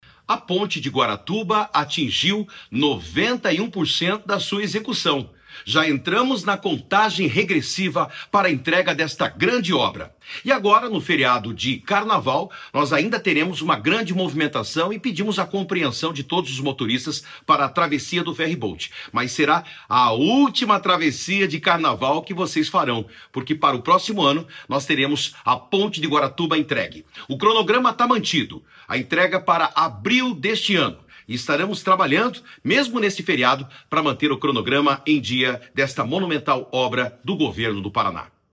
Sonora do secretário de Infraestrutura e Logística, Sandro Alex, sobre o andamento da obra da Ponte de Guaratuba